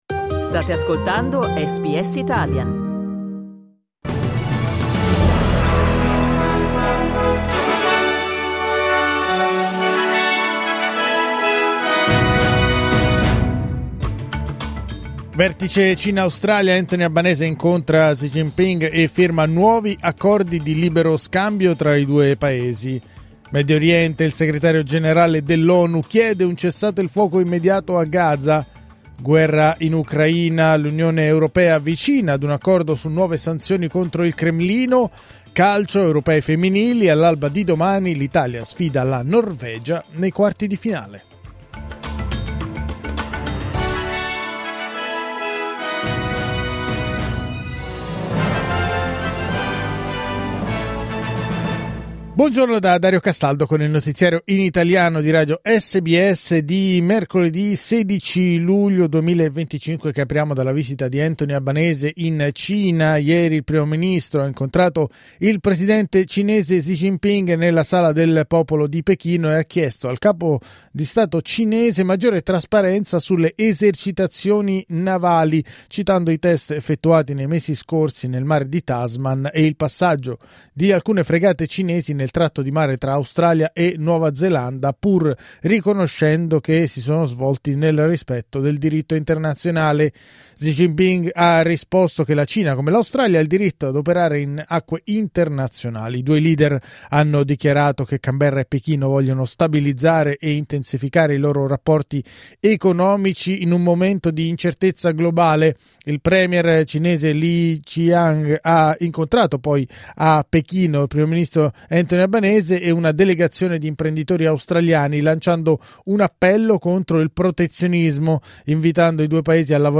Giornale radio mercoledì 16 luglio 2025
Il notiziario di SBS in italiano.